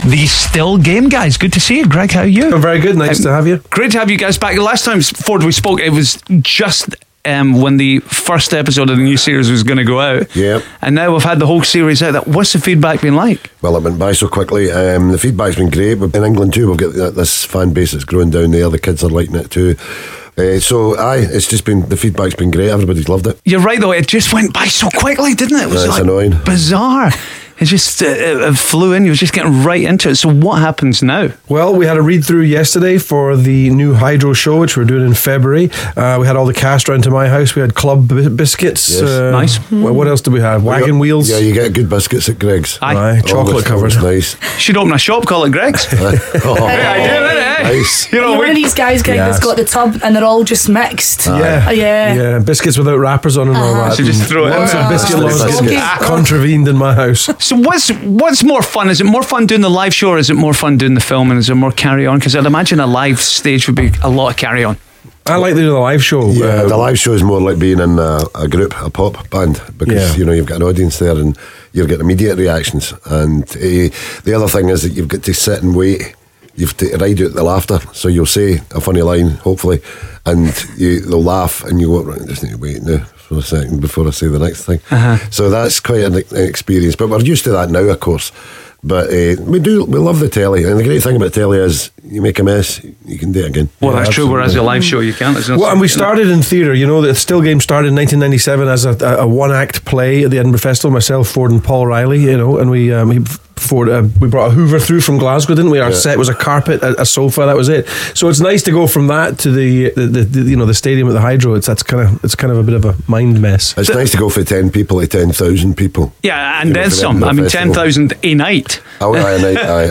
Ford & Greg AKA Jack & Victor joined us in the studio for a chat about Still Game Live 2 plus they went head to head on a special Still Game 5K Minute...